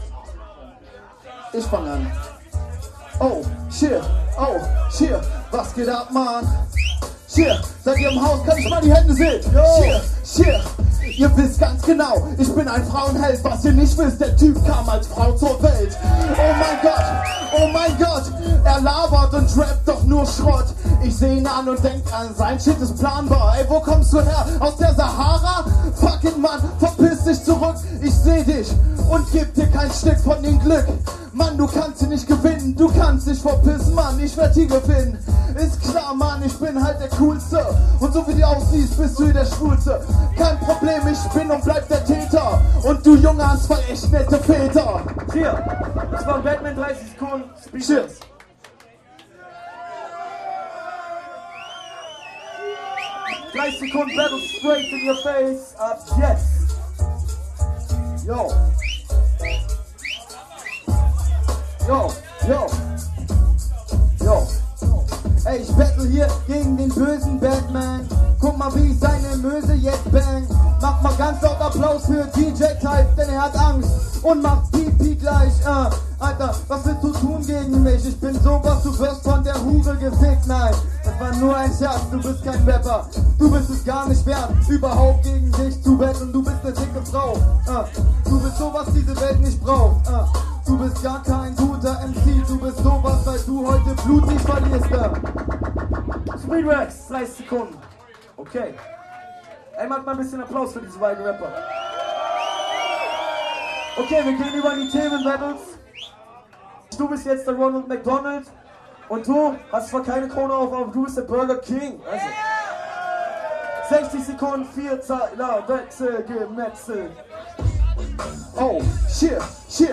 Das Halbfinale: